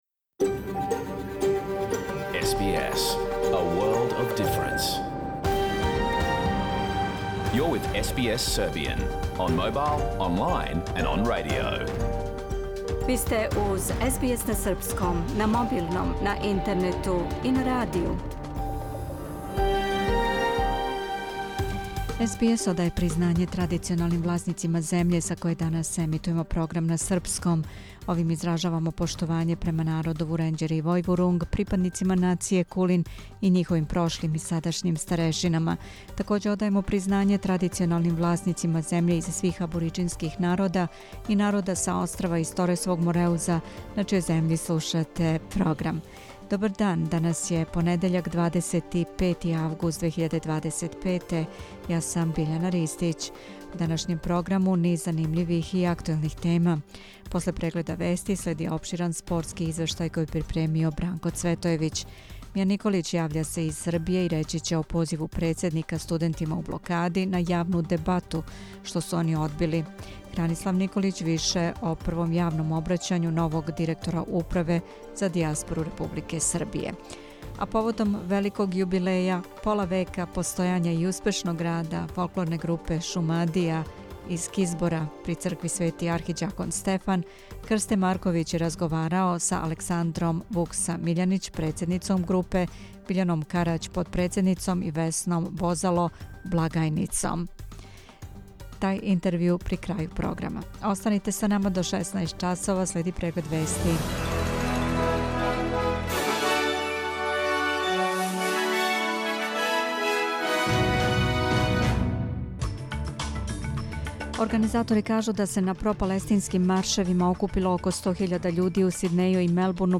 Програм емитован уживо 25. августа 2025. године